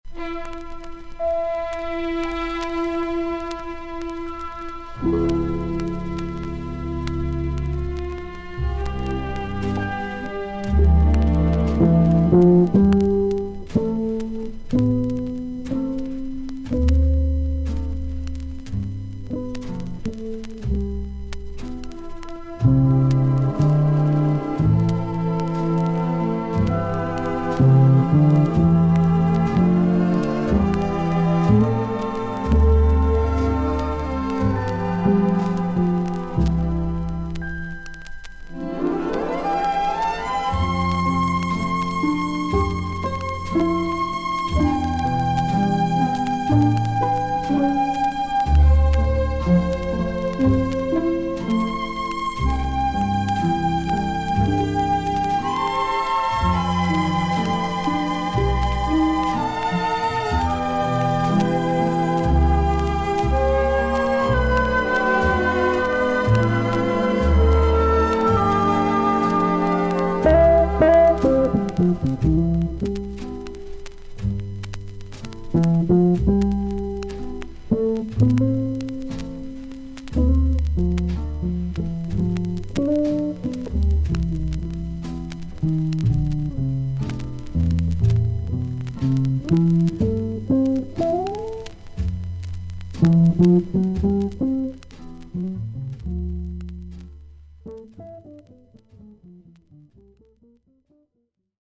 アメリカ盤 / 12インチ LP レコード / モノラル盤
少々軽いパチノイズの箇所あり。少々サーフィス・ノイズあり。クリアな音です。
B面にさわさわと周回ノイズの箇所あり。
ジャズ・ギタリスト。
優しいムードのなかスタンダード・ナンバーを演奏しています。